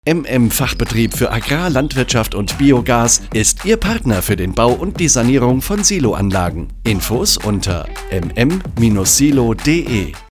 KONTAKTIEREN SIE UNS Radiospot